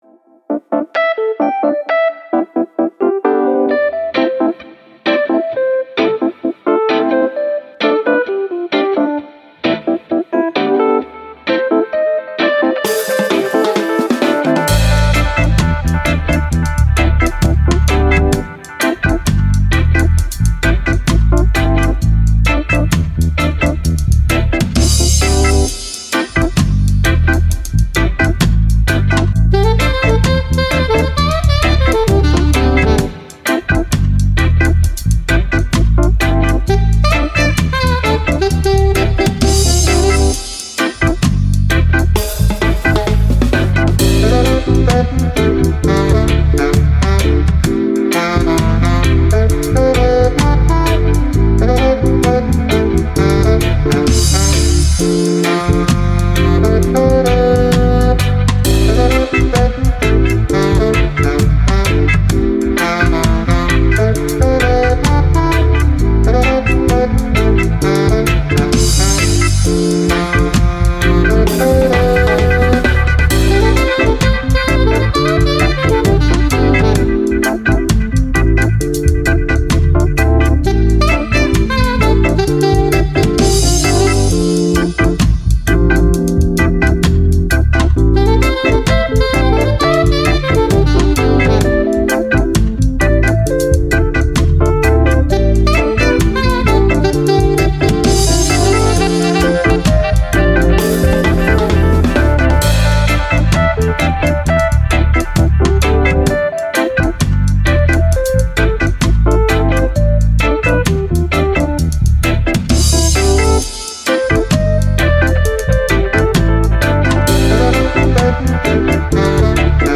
Reggae, Dub, Sun, Vibe